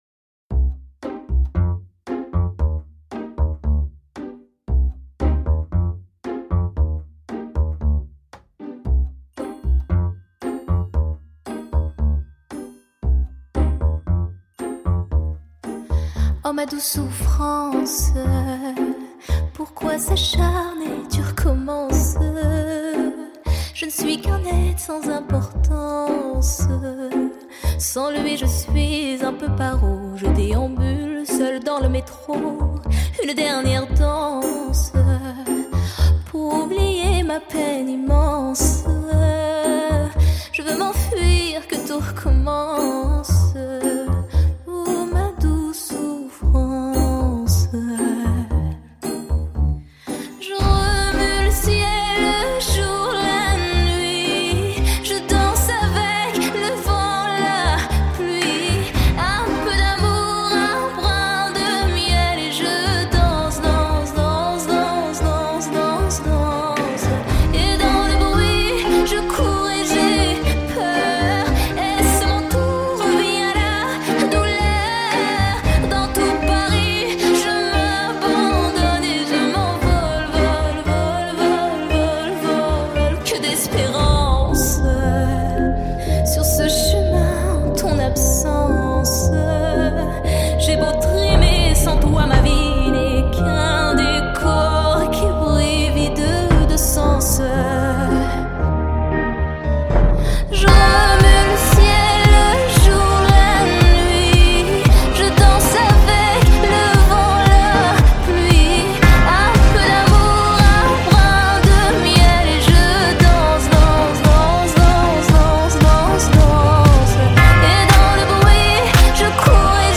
A French Pop Icon